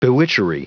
Prononciation du mot bewitchery en anglais (fichier audio)
Prononciation du mot : bewitchery